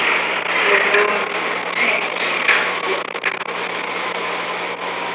Secret White House Tapes
Conversation No. 663-2
Location: Oval Office
The President met with Rose Mary Woods [?].
[Unintelligible]